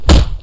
Kick3.wav